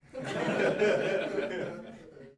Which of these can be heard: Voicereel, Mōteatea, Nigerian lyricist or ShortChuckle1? ShortChuckle1